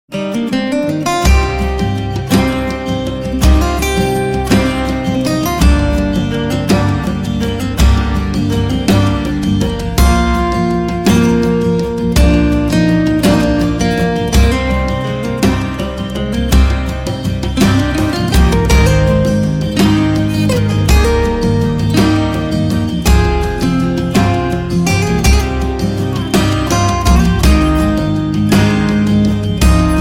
میکس با گیتار